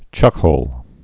(chŭkhōl)